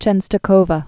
(chĕnstə-kōvə, chĕɴstô-hôvä)